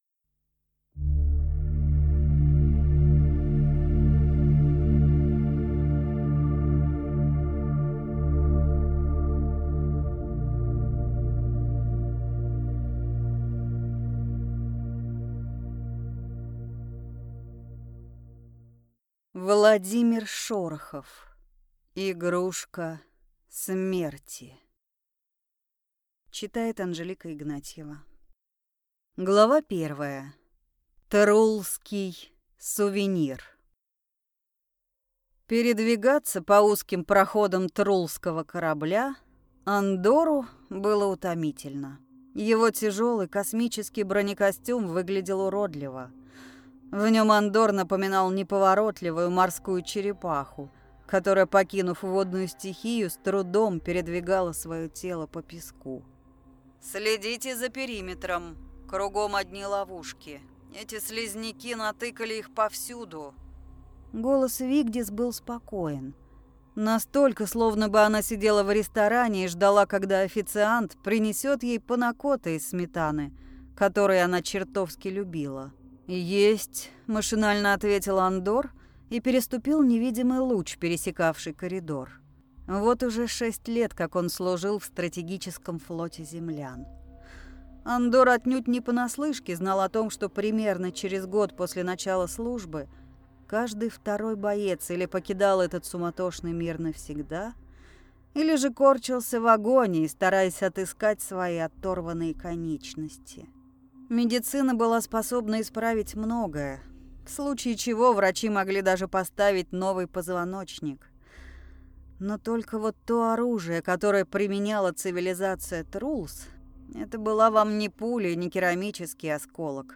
Аудиокнига Игрушка смерти | Библиотека аудиокниг